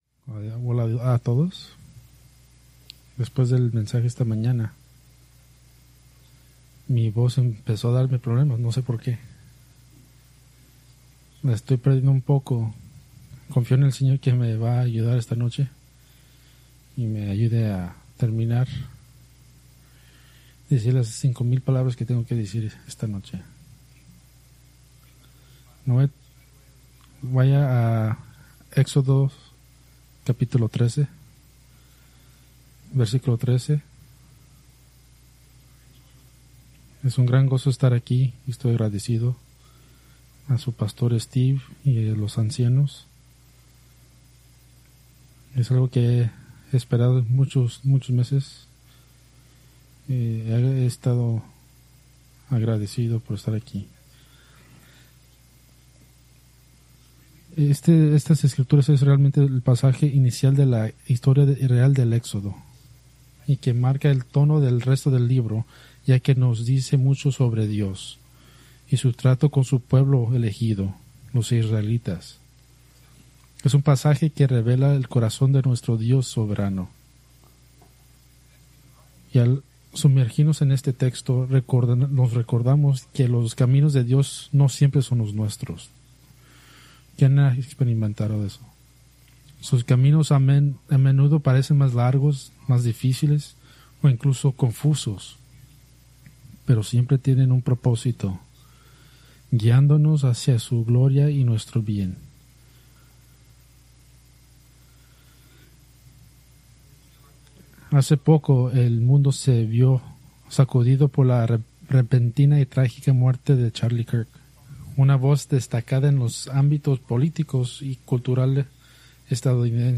Preached March 15, 2026 from Éxodo 13:17-22